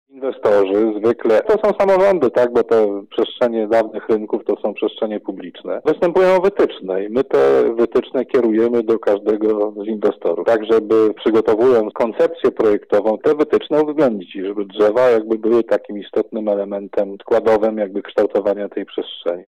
Jeżeli jest potrzeba uzasadnionej wycinki, należy wprowadzić nasadzenie zastępcze – zaznacza Lubelski Wojewódzki Konserwator Zabytków Dariusz Kopciowski: